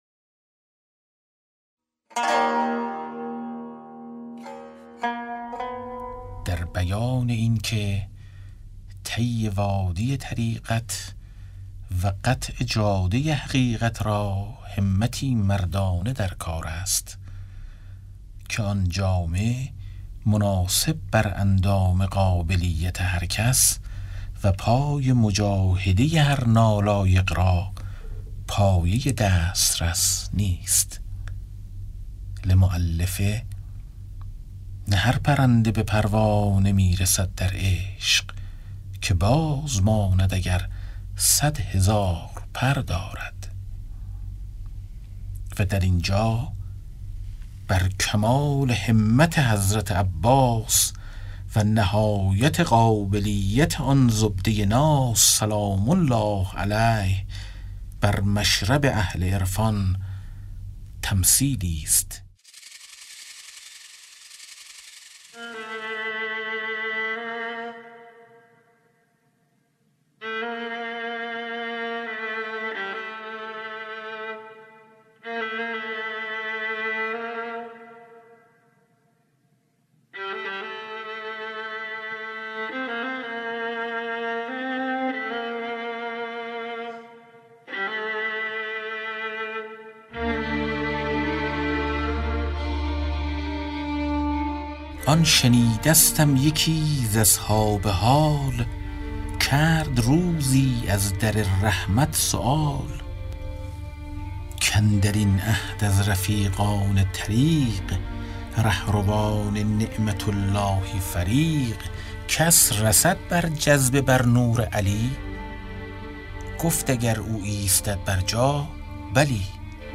کتاب صوتی گنجینه‌الاسرار، مثنوی عرفانی و حماسی در روایت حادثه عاشورا است که برای اولین‌بار و به‌صورت کامل در بیش از 40 قطعه در فایلی صوتی در اختیار دوستداران ادبیات عاشورایی قرار گرفته است.